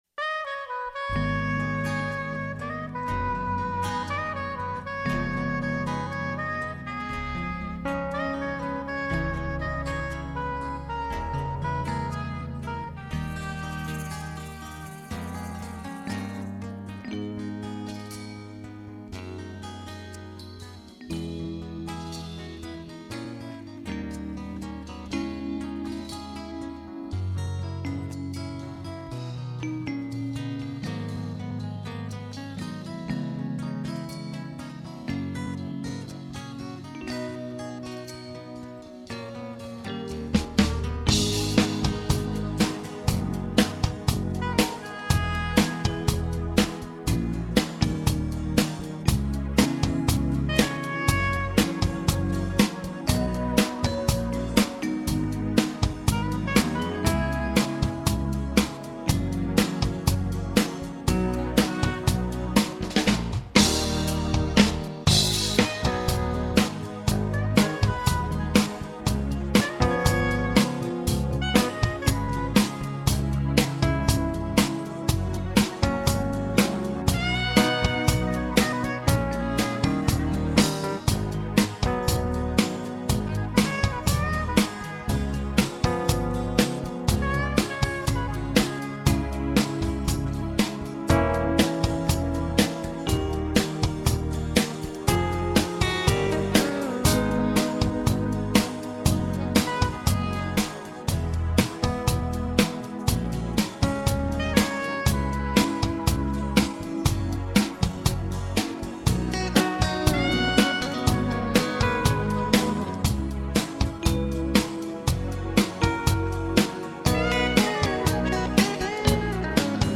Пойте караоке
минусовка версия 31138